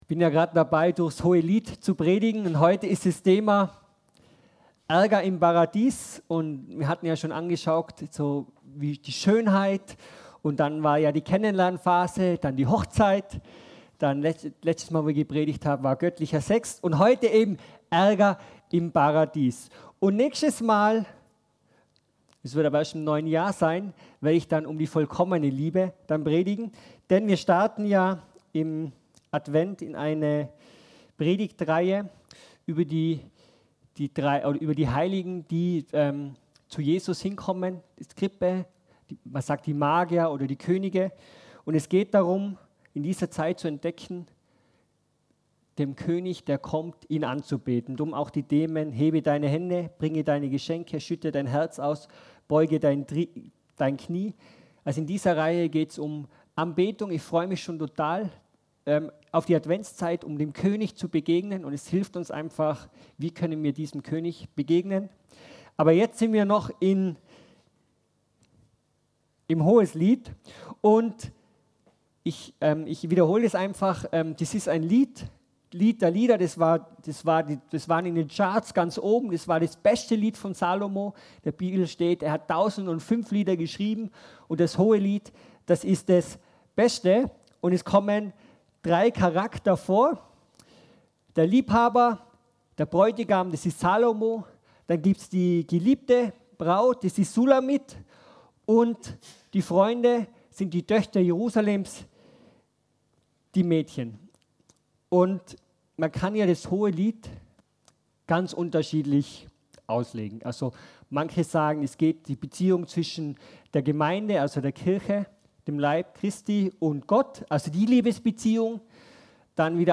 Predigt über Ärger im Paradies Hoheslied Teil 4